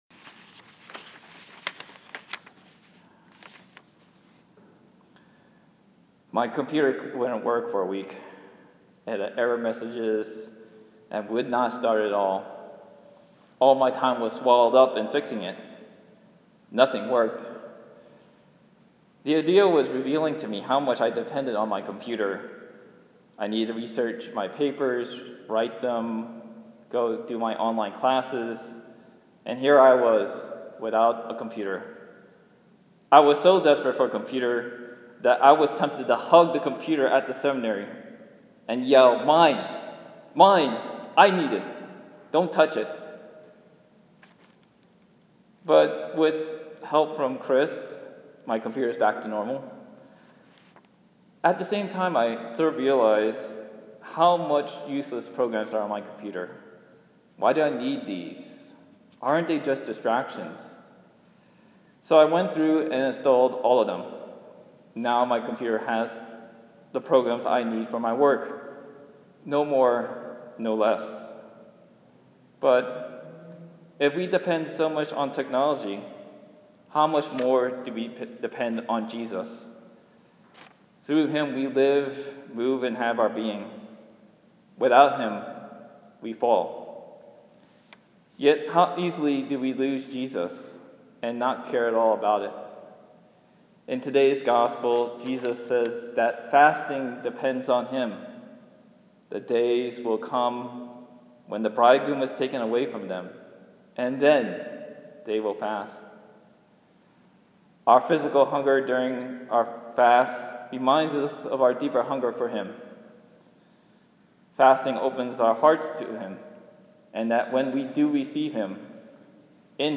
How much does fasting revolve around Jesus?   I gave this reflection during Evening Prayer on the first Friday of Lent on Feb. 28, 2020, at St. Clement Eucharistic Shrine in Boston.